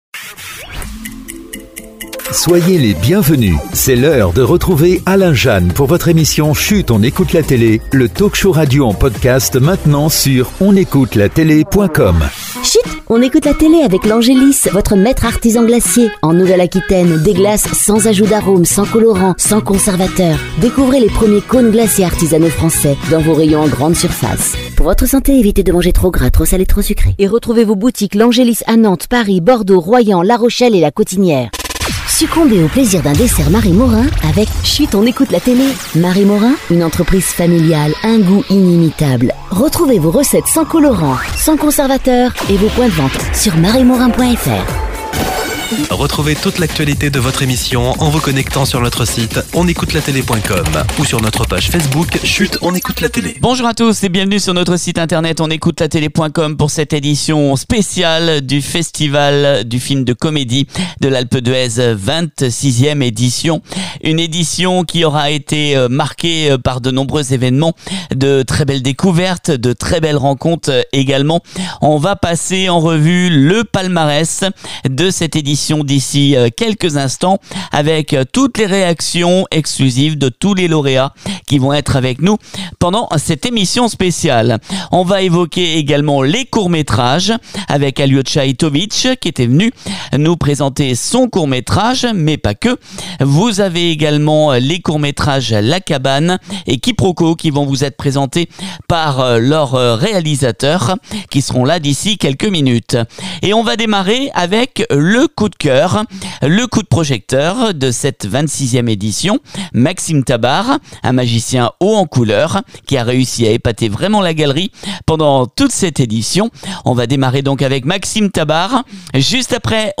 Nous vous proposons une édition spéciale 100% digitale en podcast pour cette 26ème édition du festival du film de comédie de l’Alpe d’Huez